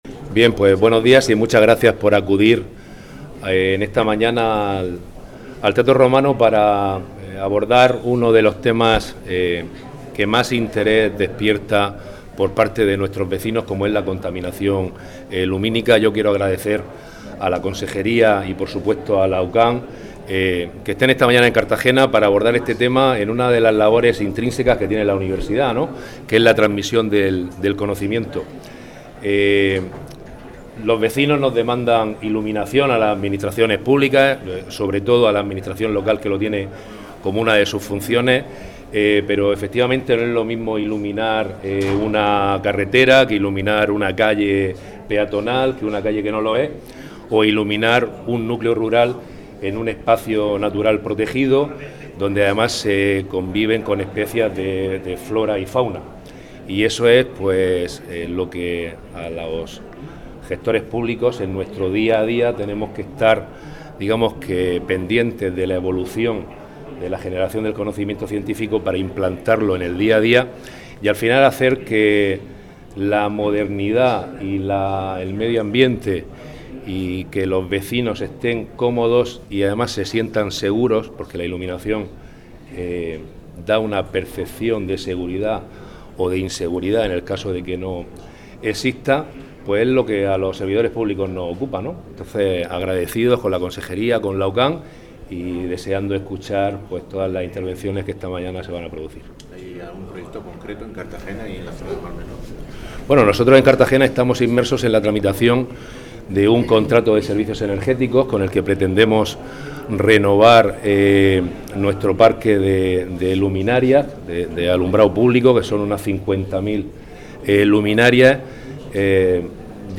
En la inauguración han intervenido el teniente alcalde de Cartagena y concejal de Infraestructuras, Diego Ortega, quien ha subrayado la necesidad de compatibilizar una iluminación adecuada con la protección del medio ambiente y la seguridad ciudadana.